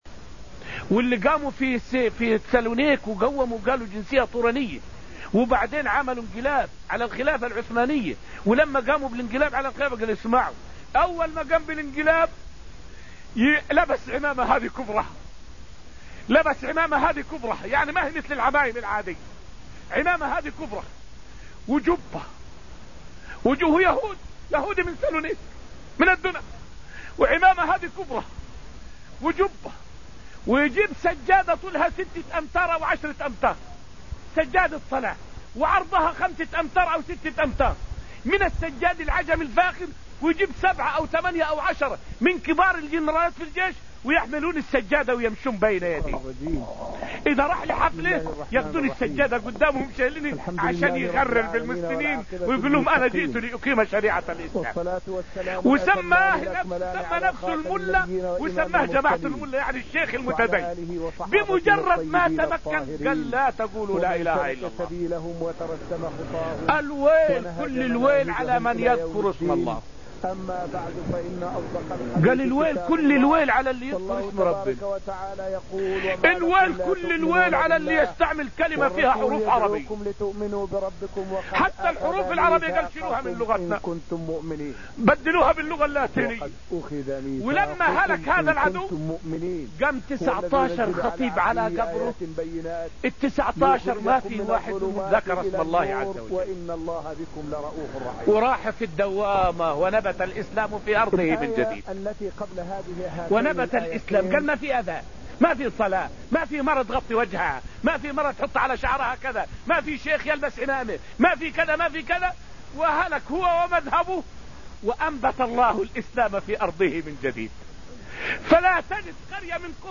فائدة من الدرس التاسع من دروس تفسير سورة الحديد والتي ألقيت في المسجد النبوي الشريف حول حال المسلمون بعد سقوط الدولة العثمانية.